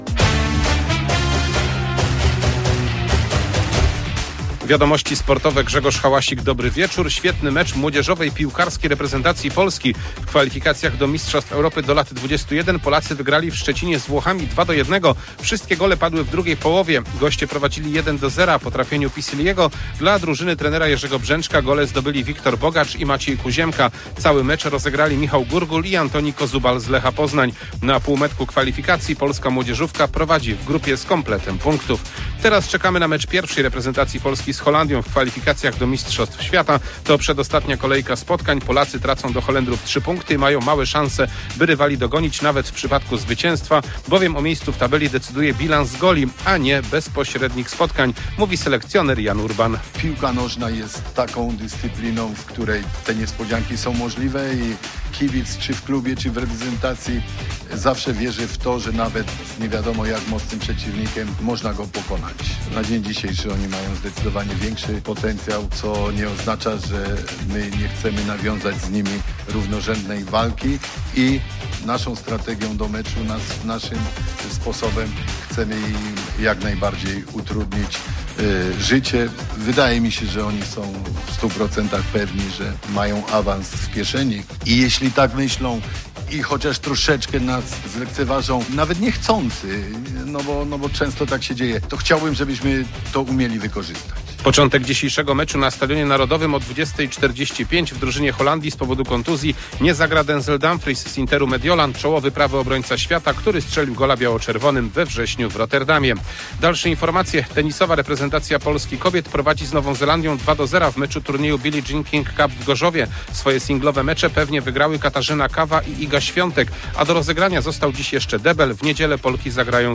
14.11.2025 SERWIS SPORTOWY GODZ. 19:05